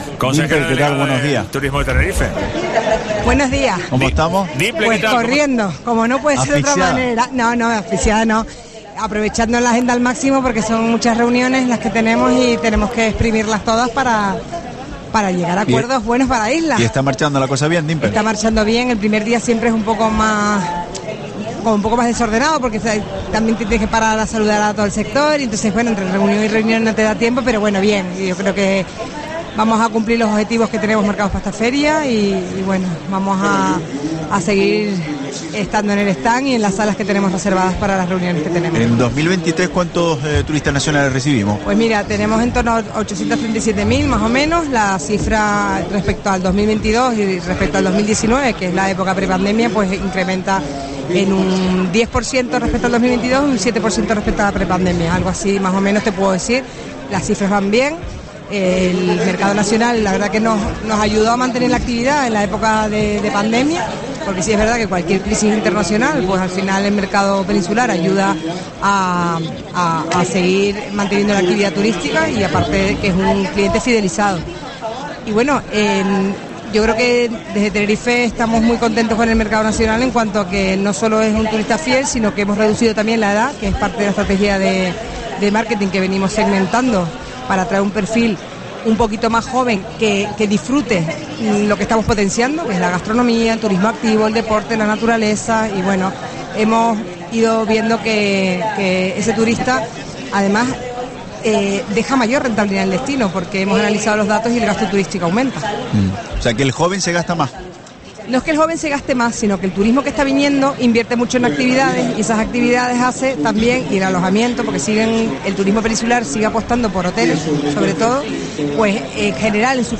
Entrevista a Dimple Melwani, consejera delegada de Turismo de Tenerife, en FITUR